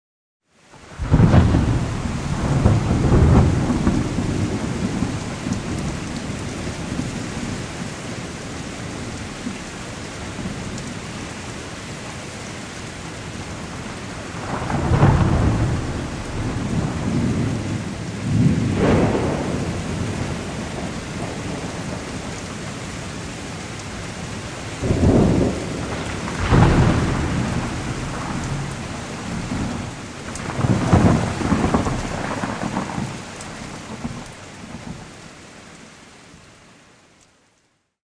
Nagranie 1 - burza z piorunami - Scenariusz 23.mp3